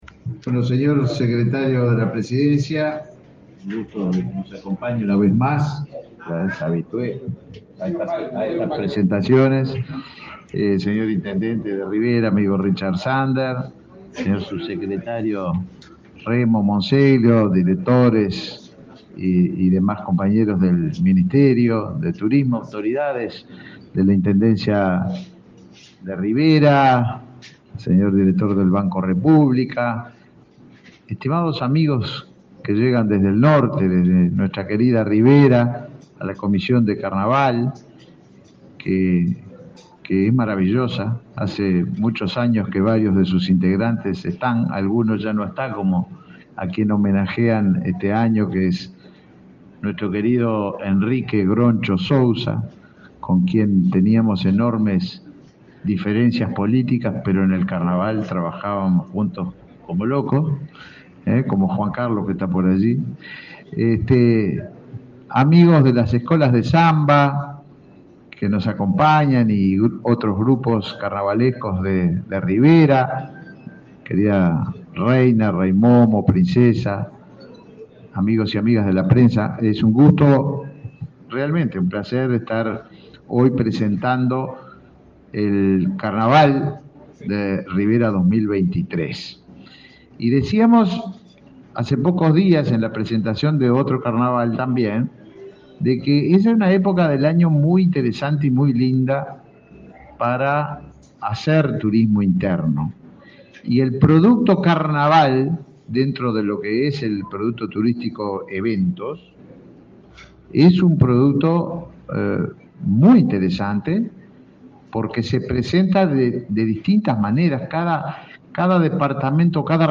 Conferencia de prensa por lanzamiento de Carnaval de Rivera
Participaron del evento el secretario de Presidencia, Álvaro Delgado, y el ministro de Turismo, Tabaré Viera.